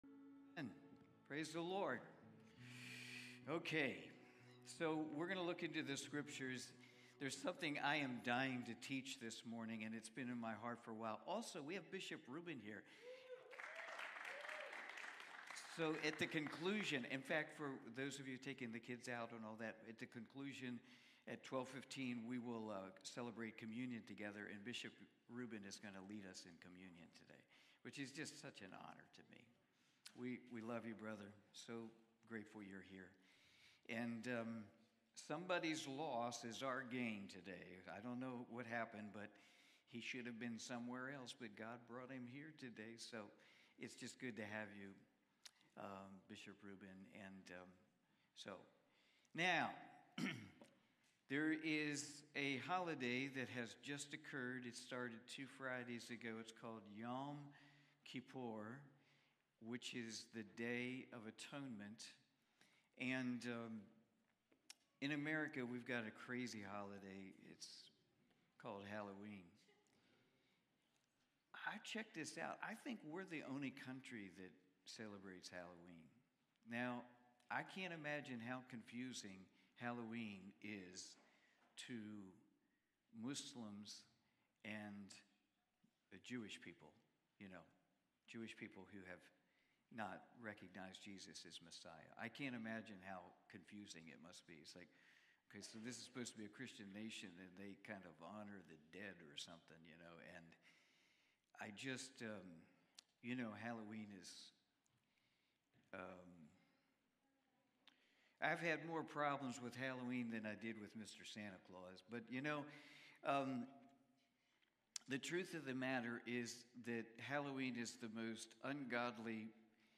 Cornerstone Fellowship Sunday morning service, livestreamed from Wormleysburg, PA.